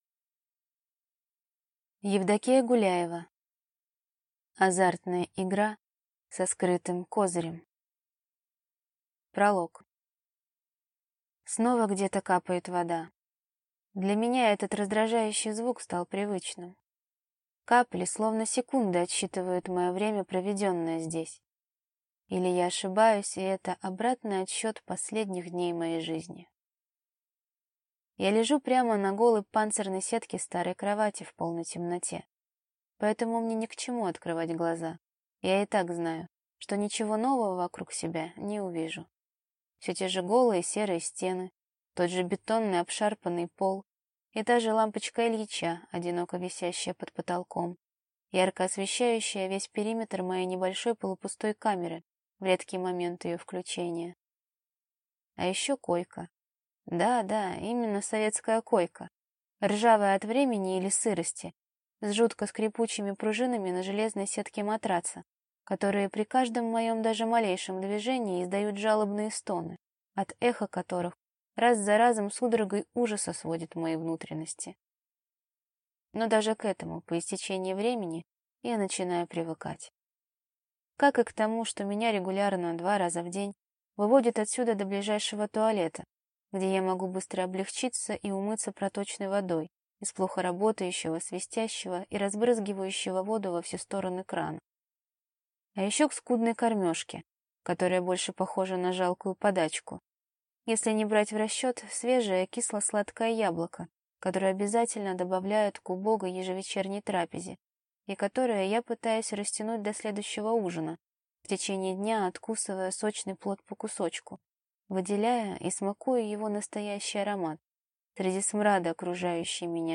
Аудиокнига Азартная игра со скрытым Козырем | Библиотека аудиокниг